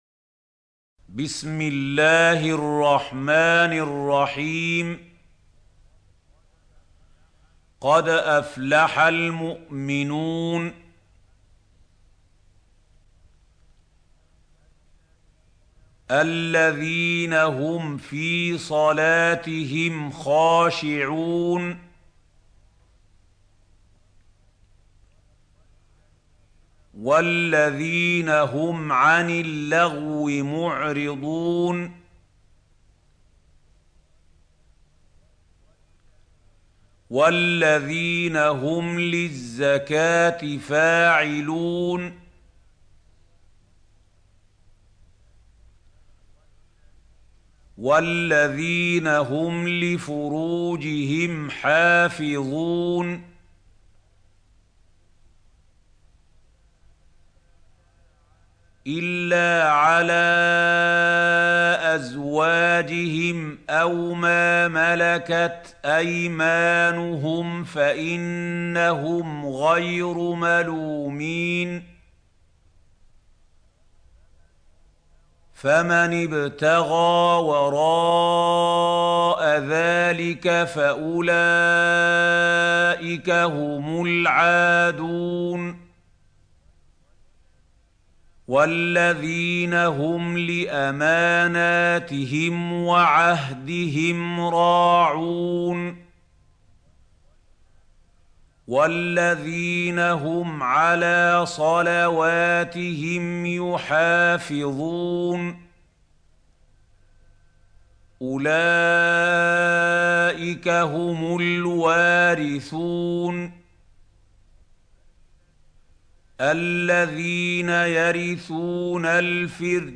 سورة المؤمنون | القارئ محمود خليل الحصري - المصحف المعلم